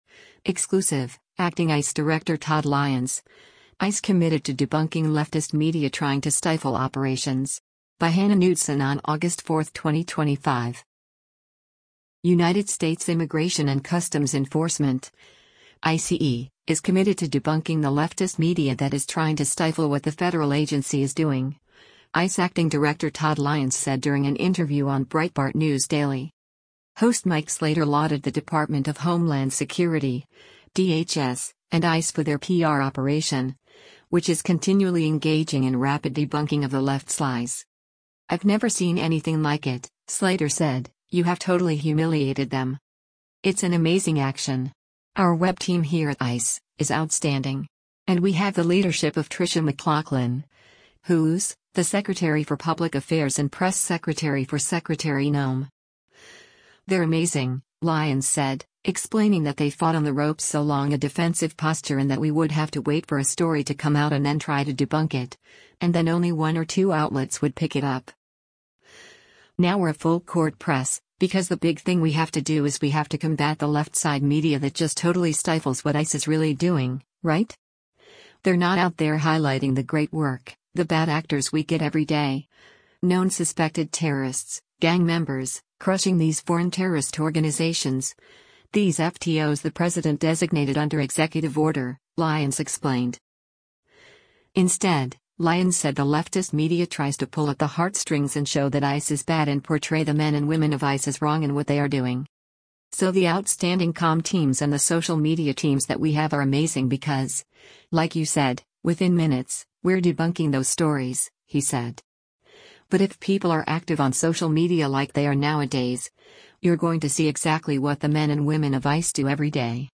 United States Immigration and Customs Enforcement (ICE) is committed to debunking the leftist media that is trying to stifle what the federal agency is doing, ICE Acting Director Todd Lyons said during an interview on Breitbart News Daily.